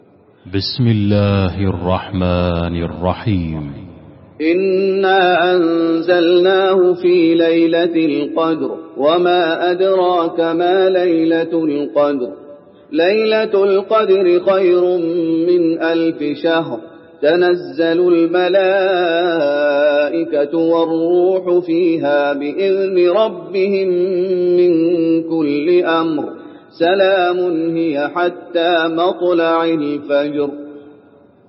المكان: المسجد النبوي القدر